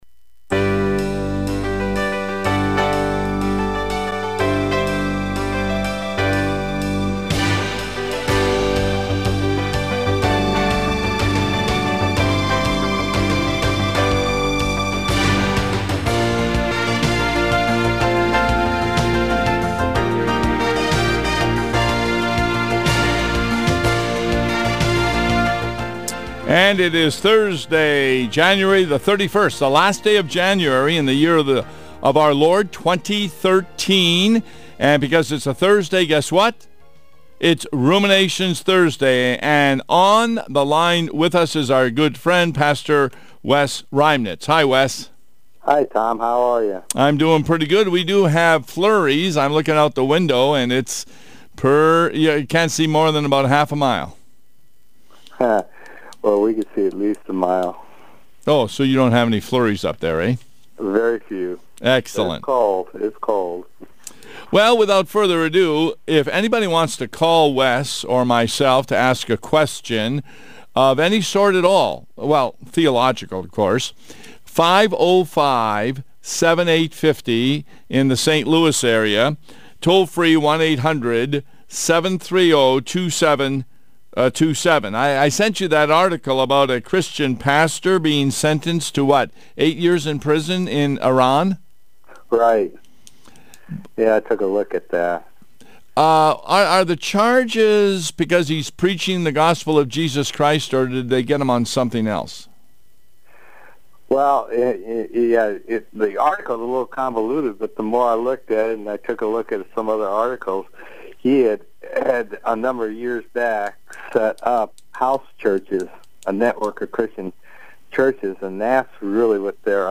(2) Iranian court sentenced Christian pastor to eight years in prison. (3) Persecution in the U.S. (4) Caller: “Blue Bloods” great TV show.